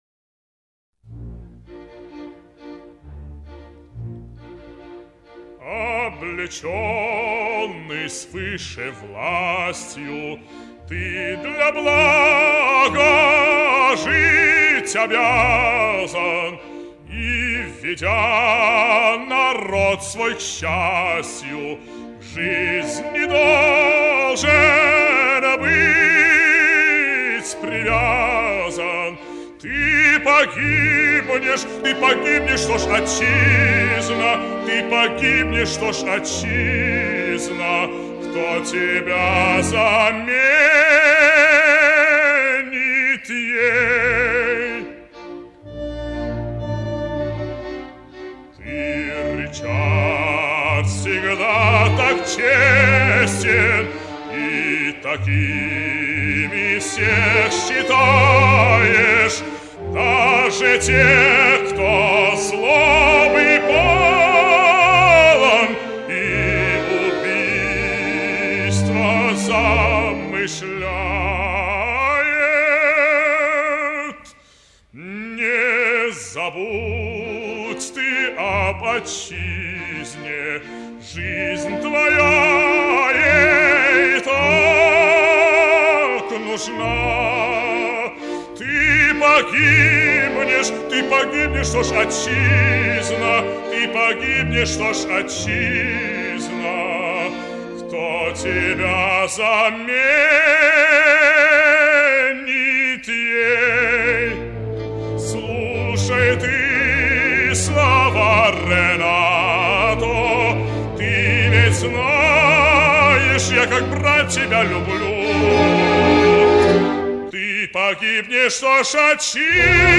Ëèñèöèàí, êîíå÷íî, ìàñòåð ìèðîâîãî óðîâíÿ.
Òîò æå îáú¸ì è ÿðêîñòü ãîëîñà.
ìíå íåïîíðàâèëîñü ó Ëèñèöûíà áåñêîíå÷íîå âèáðàòòî... êàê òðåìîëî.. ðàçäðàæàåò... à â îñòàëüíîì î÷ êë¸âî)))
ariozo_renato.mp3